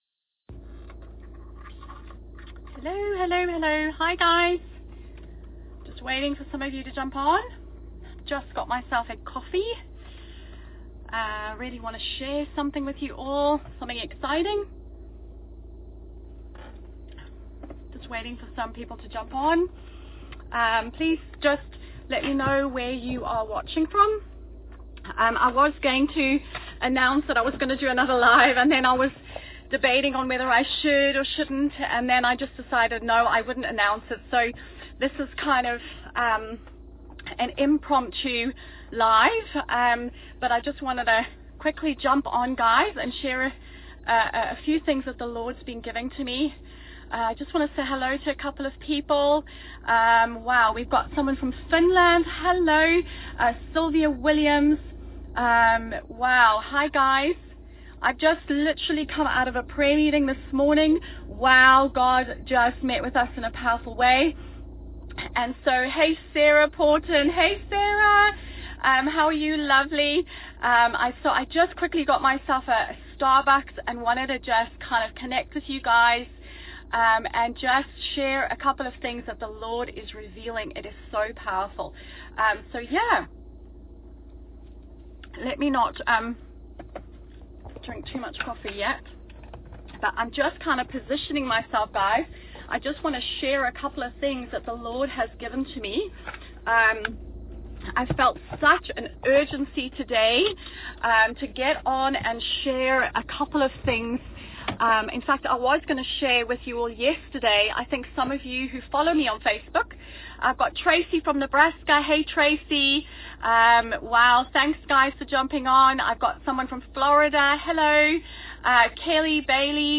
This live Facebook video was just released today.